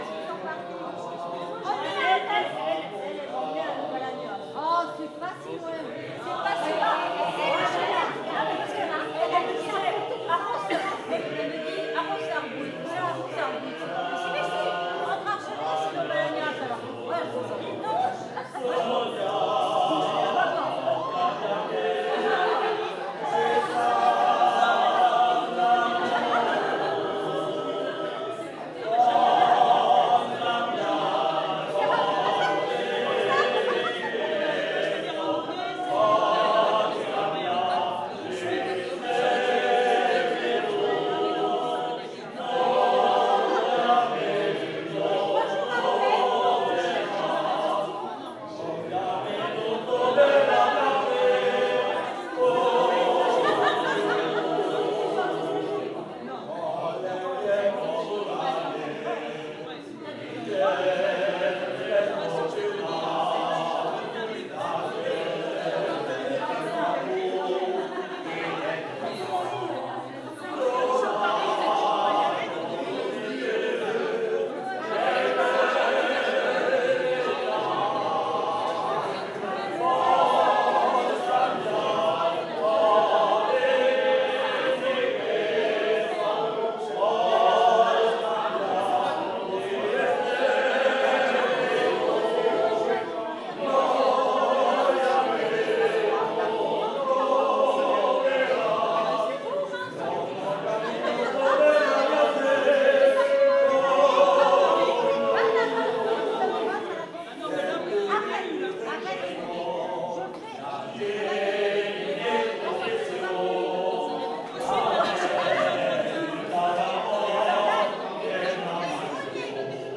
Aire culturelle : Bigorre
Lieu : Ayros-Arbouix
Genre : chant
Type de voix : voix mixtes
Production du son : chanté
Descripteurs : polyphonie
Notes consultables : Enregistrement coupé avant la fin. Bruits de conversation.